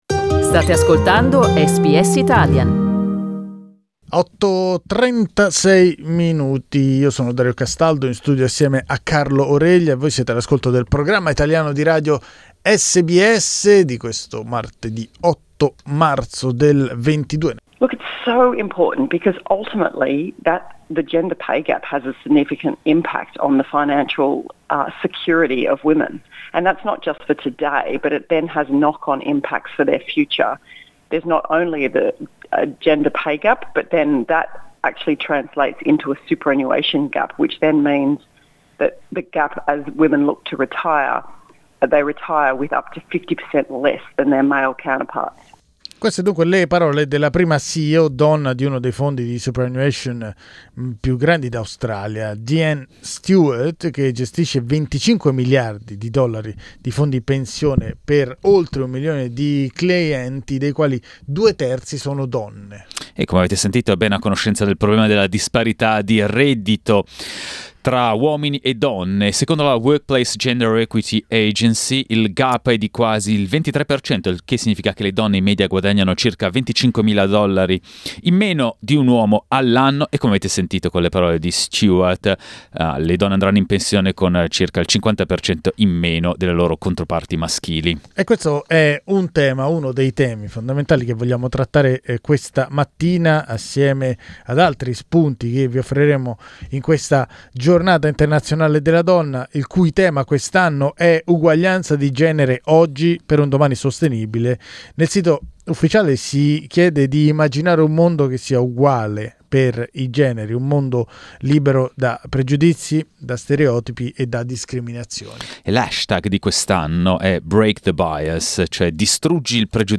SBS Italian, insieme a tre ospiti, esplora tre temi diversi per capire come si possa raggiungere la parità attraverso il cambiamento.
Ascolta il dibattito: LISTEN TO Giornata internazionale della Donna, pregiudizi e uguaglianza di genere SBS Italian 20:51 Italian Le persone in Australia devono stare ad almeno 1,5 metri di distanza dagli altri.